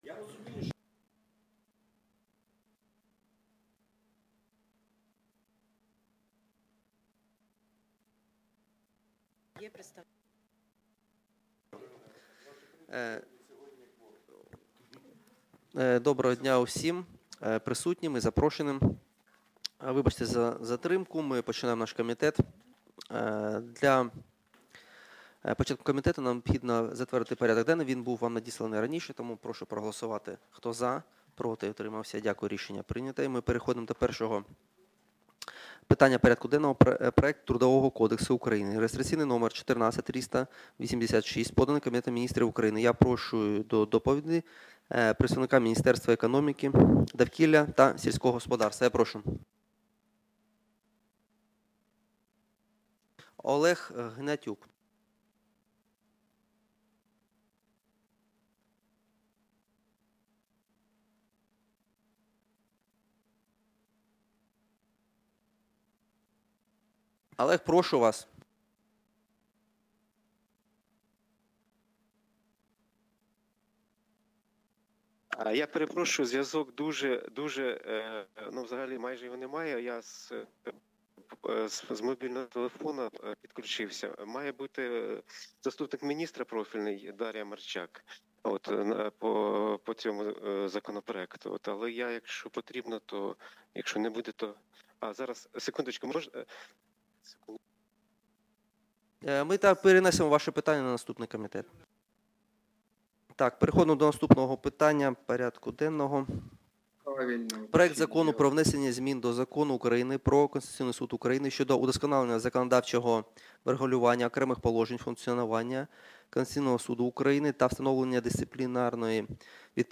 Аудіозапис засідання Комітету від 10.03.2026